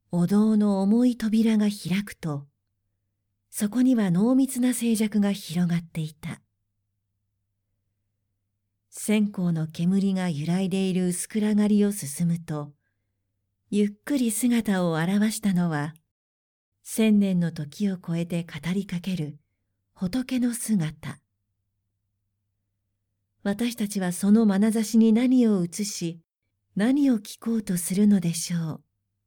クセのない素直な声質で、明るく元気なものから落ち着いたものまで対応可能です。
– ナレーション –
しっとり、重厚な
female59_11_2.mp3